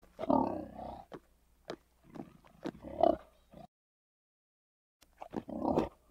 Все записи сделаны в естественной среде обитания животного.
Бородавочник - Альтернативный вариант